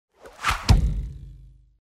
Звуки метания ножа
Вы можете слушать онлайн или скачать резкие, свистящие звуки полета и точные удары лезвия о деревянную мишень.
Тяжелый нож вонзился в дверь